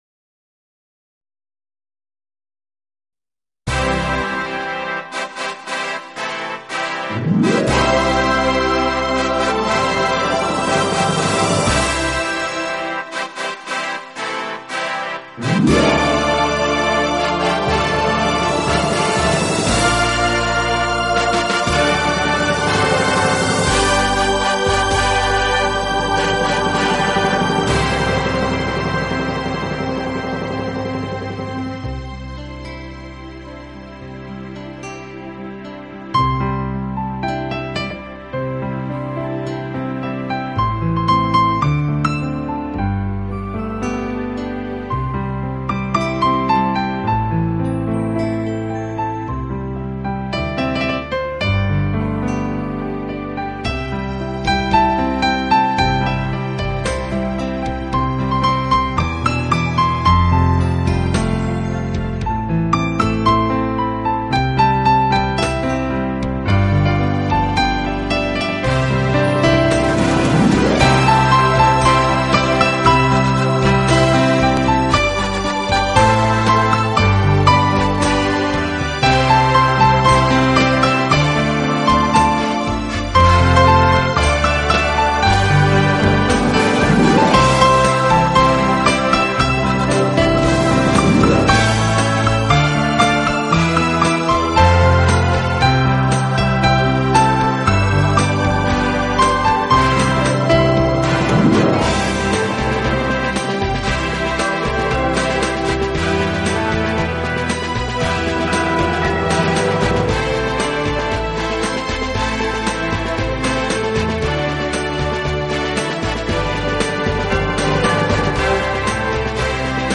【轻音钢琴】
曲调熟悉，意境优美，既有时代名曲，也有丰富多彩的风情民歌。